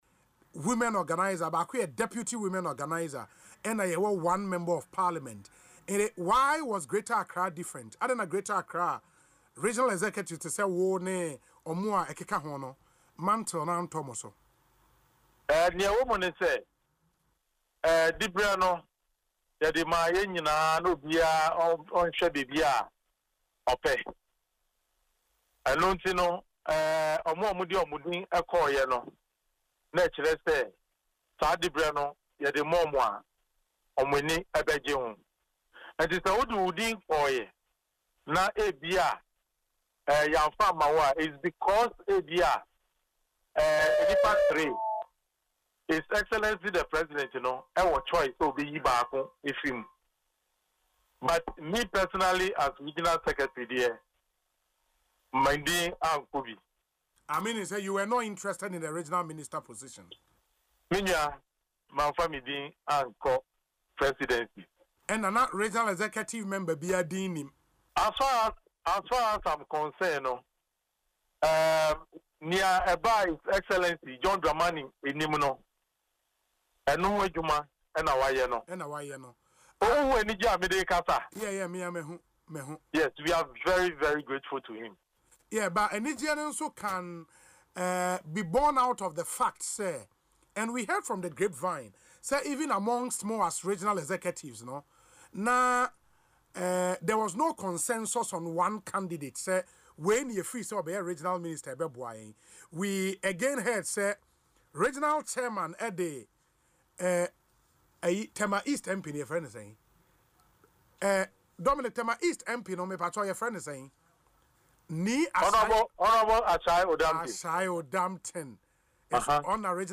In an interview on Asempa FMs Ekosii Sen show